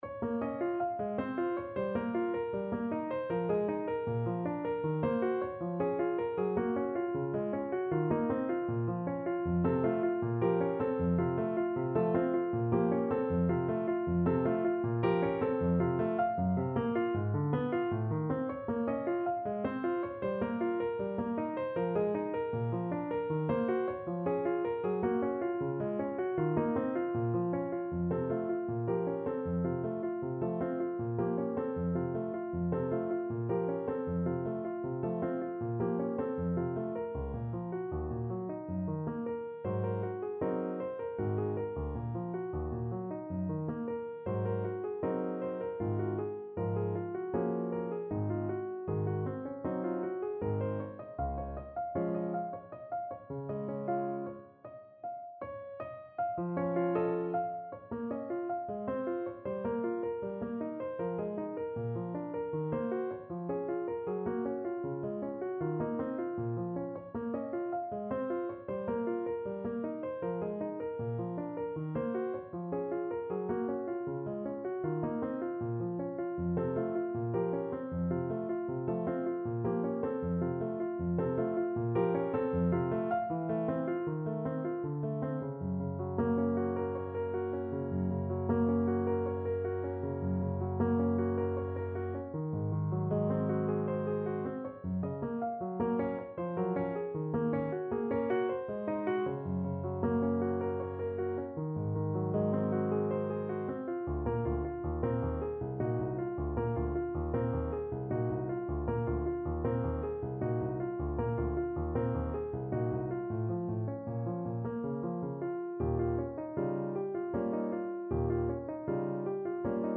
3/4 (View more 3/4 Music)
Classical (View more Classical Voice Music)